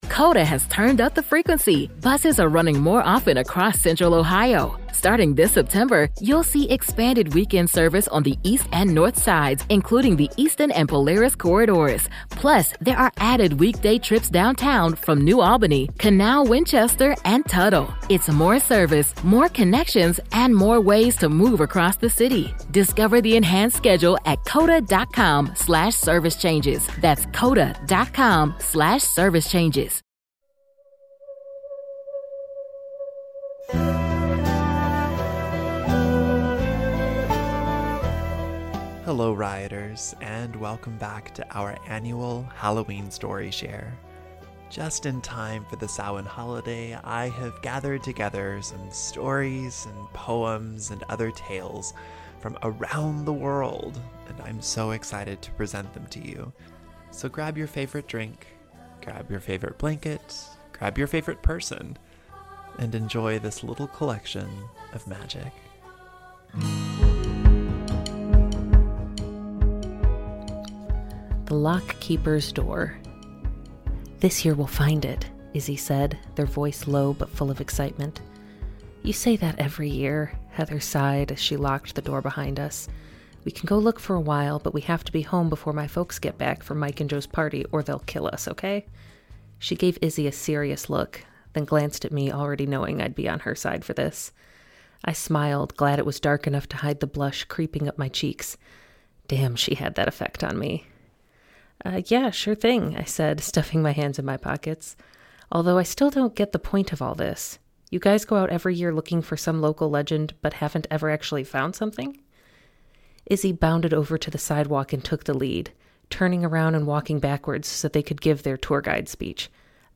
a full-cast short story